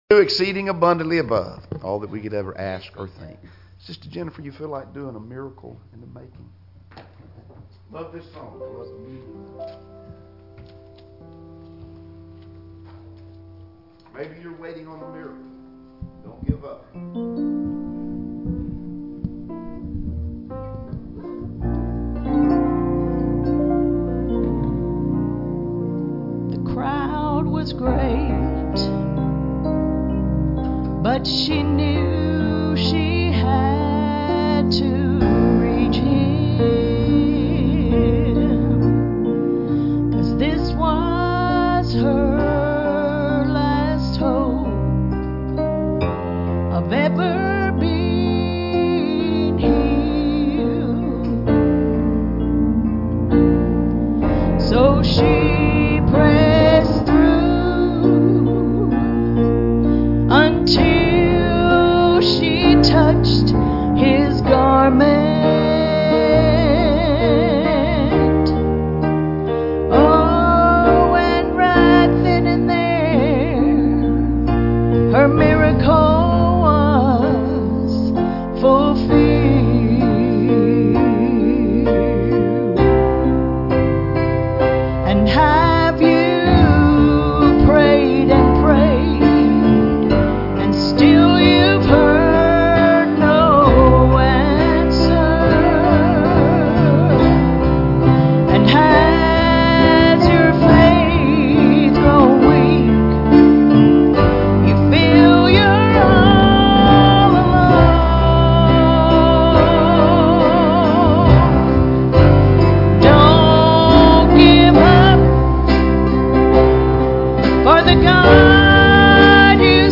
Passage: "Joshua 5:10-12" Service Type: Sunday Evening Services Topics